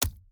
Sfx_creature_rockpuncher_walk_slow_left_legs_04.ogg